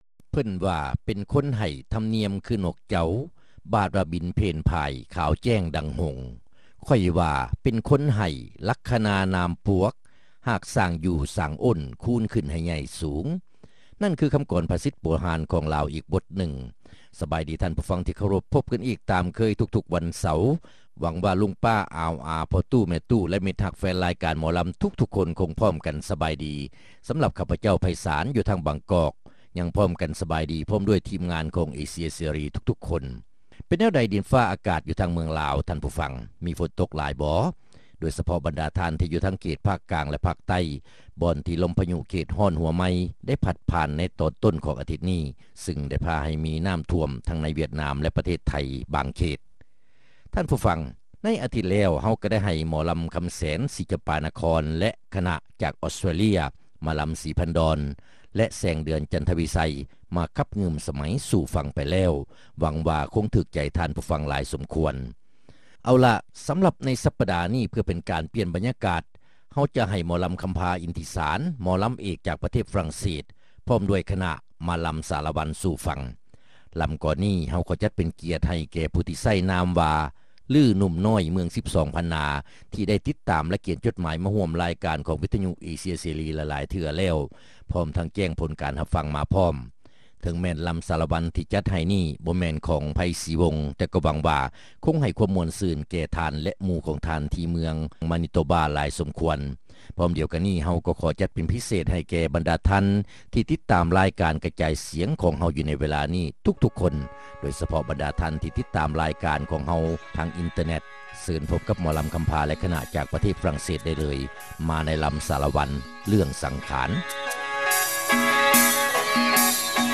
ຣາຍການໜໍລຳ ປະຈຳສັປະດາ ວັນທີ 16 ເດືອນ ກັນຍາ ປີ 2005